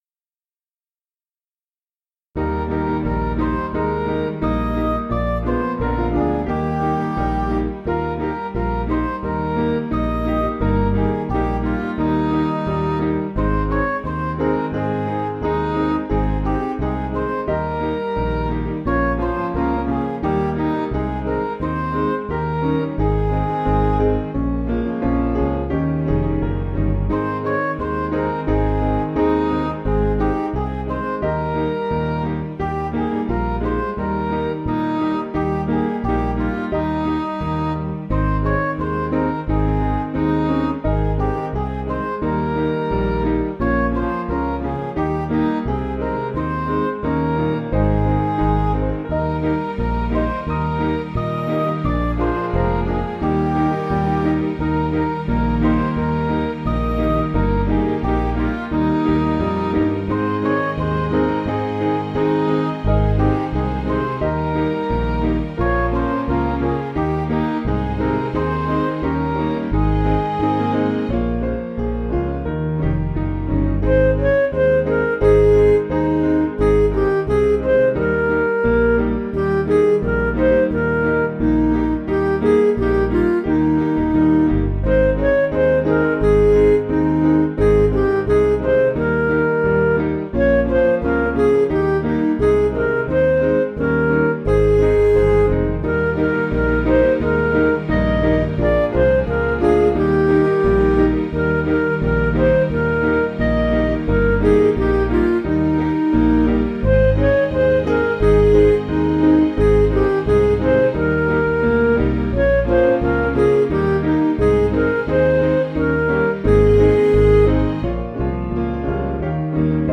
Piano & Instrumental
(CM)   3/Ab